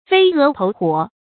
注音：ㄈㄟ ㄜˊ ㄊㄡˊ ㄏㄨㄛˇ
飛蛾投火的讀法